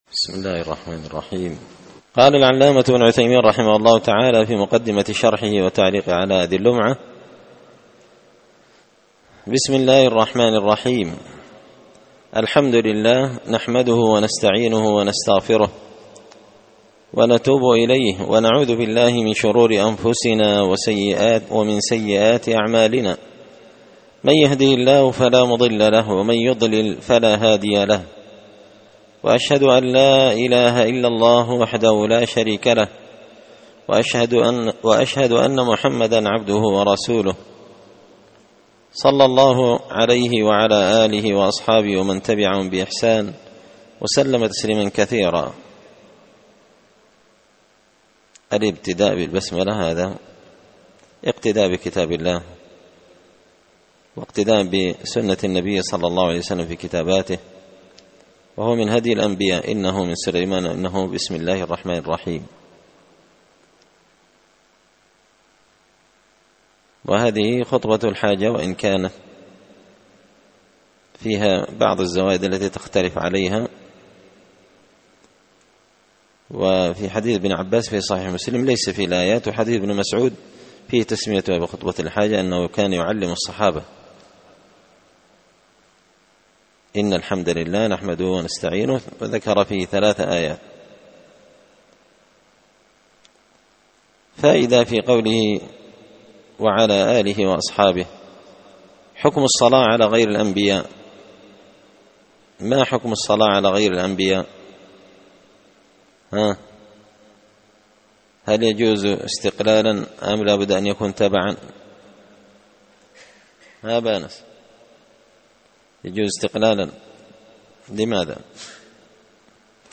شرح لمعة الاعتقاد ـ الدرس 2
دار الحديث بمسجد الفرقان ـ قشن ـ المهرة ـ اليمن